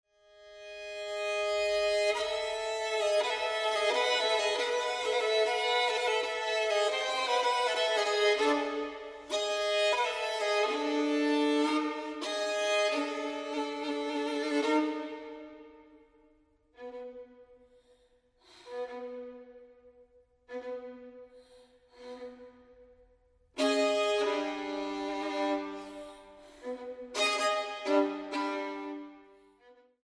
Improvisación